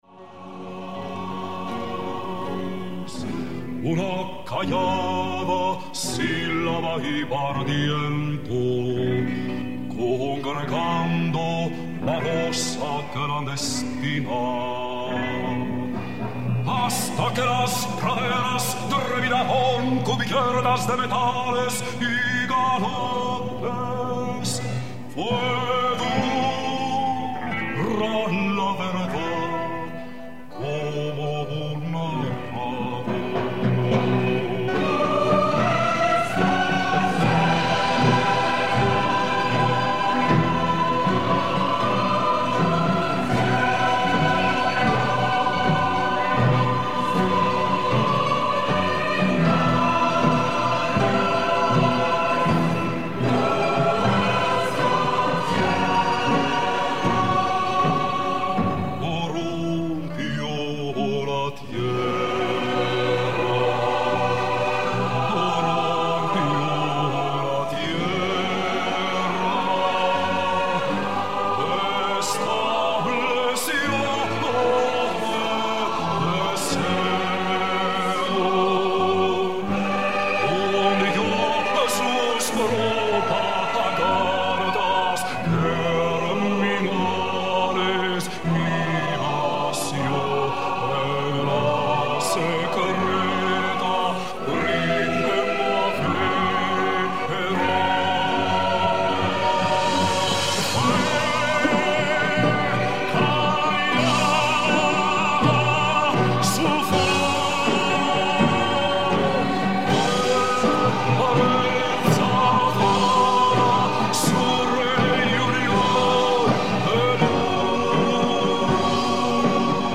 POUR ENTENDRE LA PRONONCIATION EN ESPAGNOL